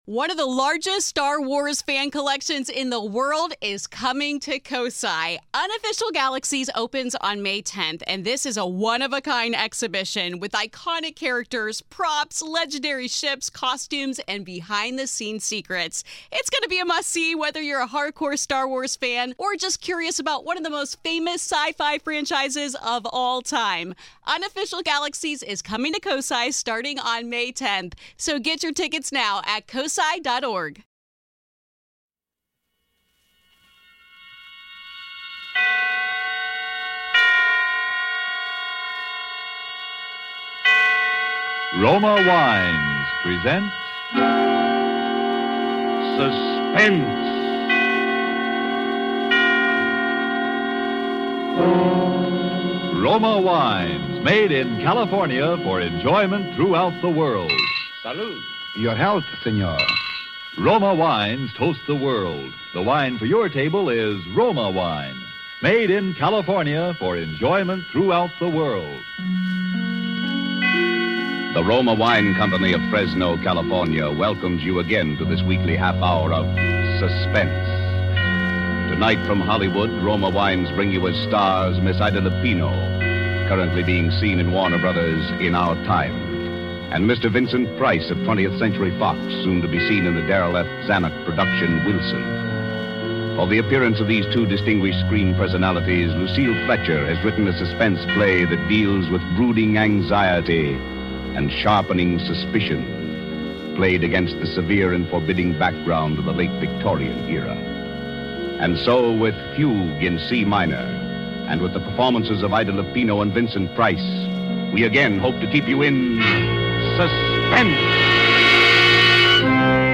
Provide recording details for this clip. On this episode of the Old Time Radiocast we present you with two stories from the classic radio program Suspense!